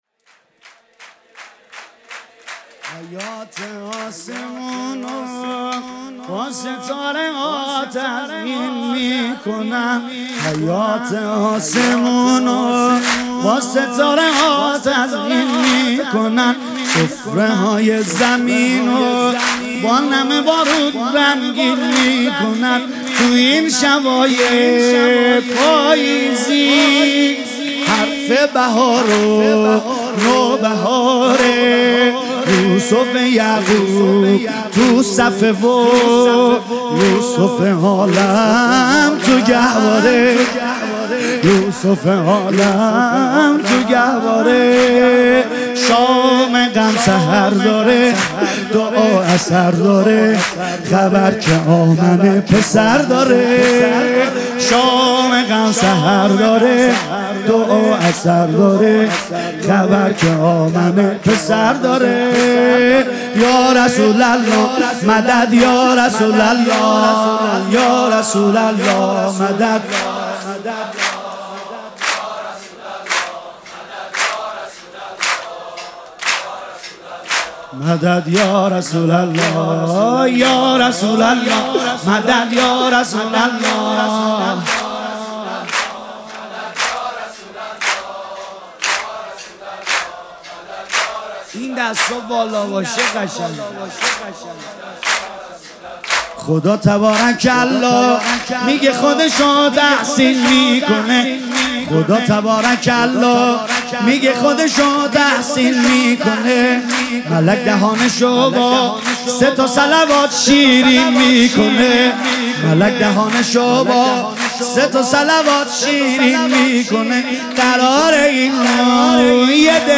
مولودی- حیاط آسمونو با ستاره ها تزیین میکنن